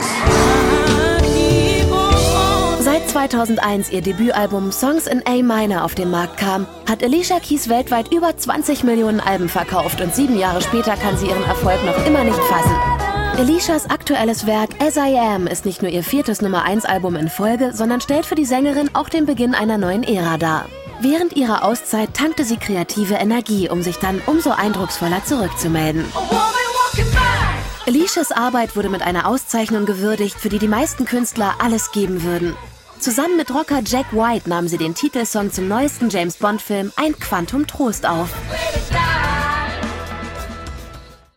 deutsche Sprcherin, schön, jung, klar, flexibel
Sprechprobe: Sonstiges (Muttersprache):
german female voice over artist